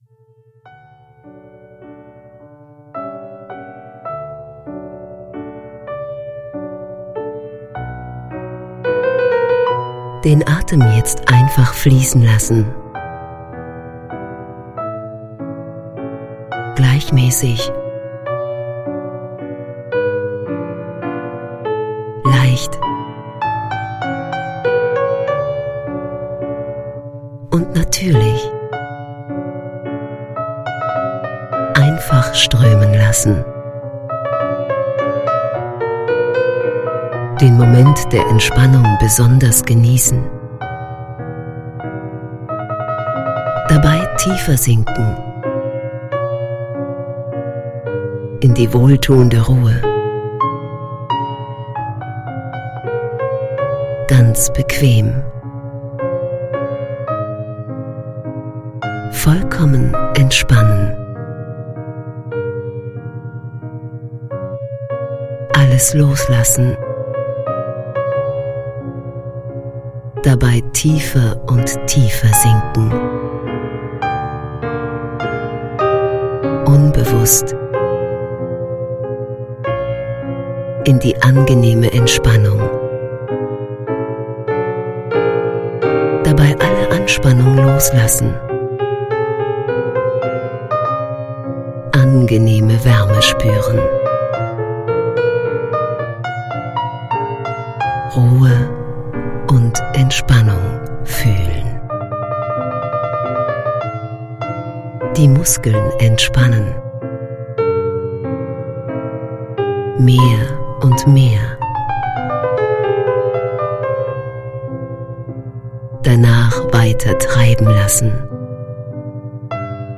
Mehr Ruhe, Gelassenheit & Kraft durch Tiefenentspannung – Gleiten Sie in wunderbare Entspannungszustände durch die angenehme Stimme und die klassische Hintergrundmusik.
Dieses Hörbuch ist in professioneller Tonstudioqualität von erfahrenen Entspannungstherapeuten erstellt worden.
Die spezielle neurologische Hintergrundmusik stimuliert die Gehirnfrequenz so, dass Sie unmittelbar nach der Anwendung sofort tiefe Erholung spüren werden.